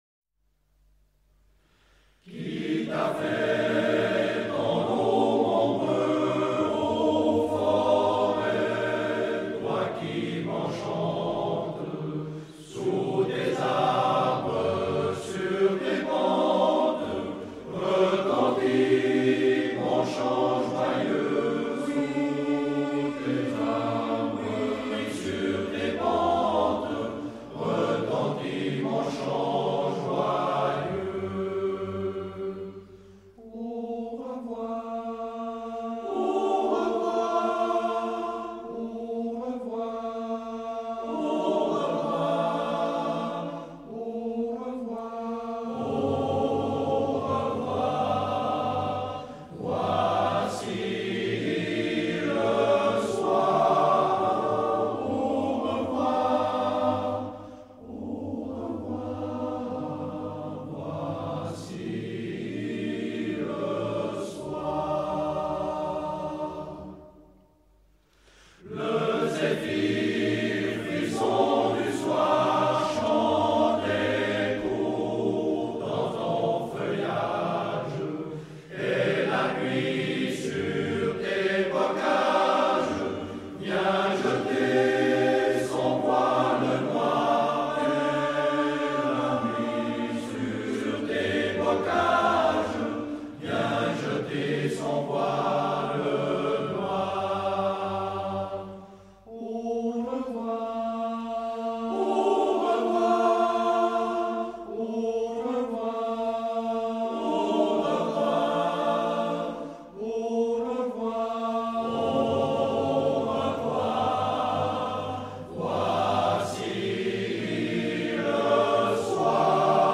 Chœur d’hommes fondé en 1860
Version originale
par La Concorde, Éclépens